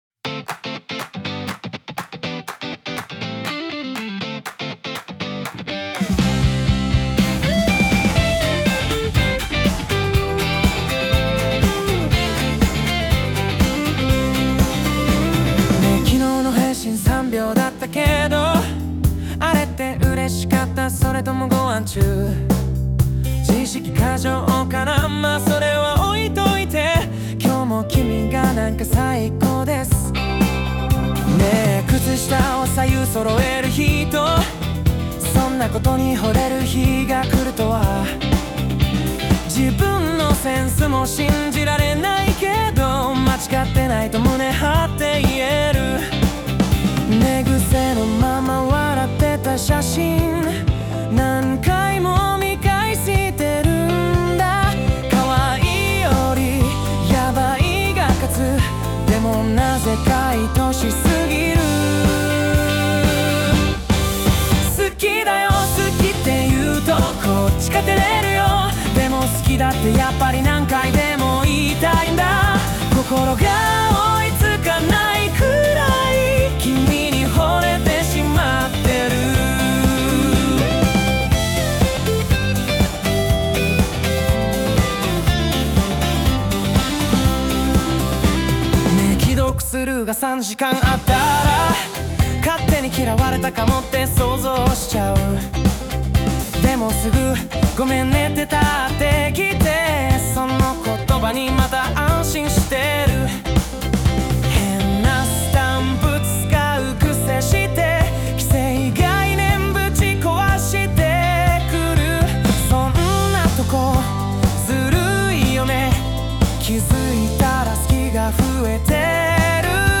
邦楽男性ボーカル著作権フリーBGM ボーカル
著作権フリーオリジナルBGMです。
男性ボーカル（邦楽・日本語）曲です。